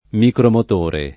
micromotore [ mikromot 1 re ]